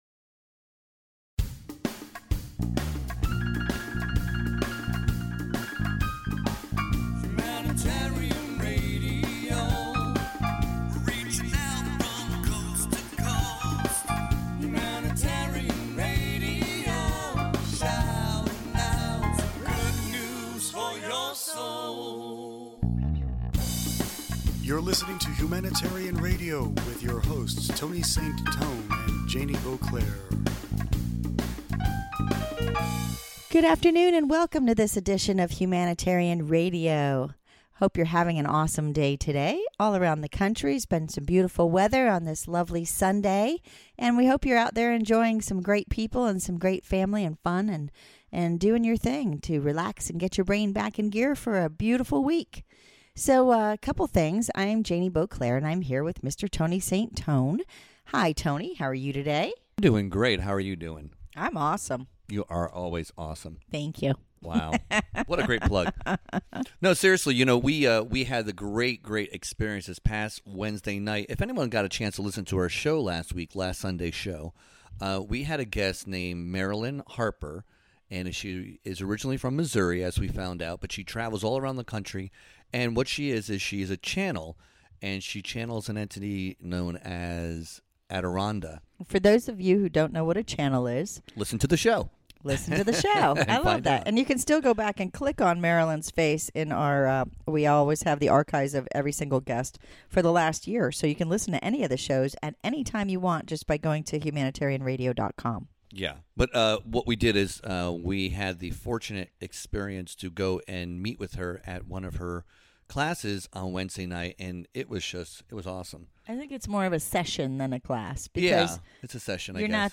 Entertainment HR Interview